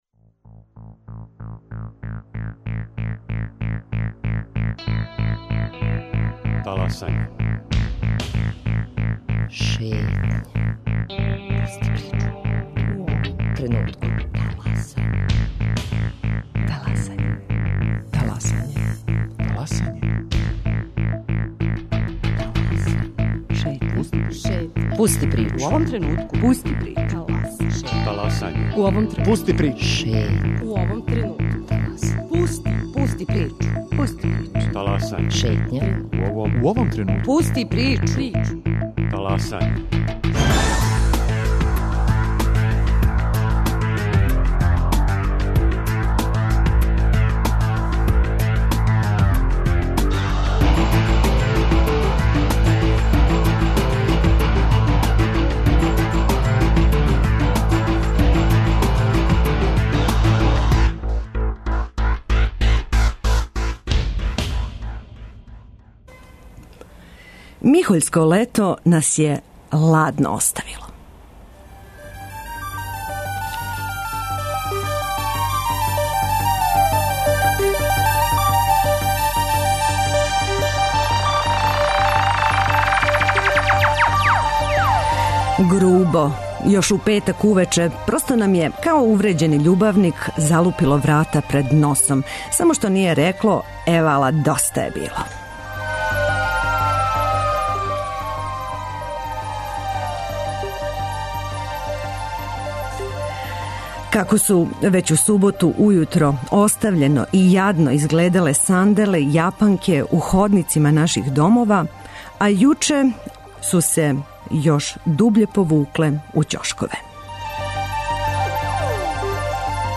После 10.40 отворићемо програм и за ваша укључења, како би смо добро припремљени ушли у други сат Таласања када ћемо говорити о менталном здрављу.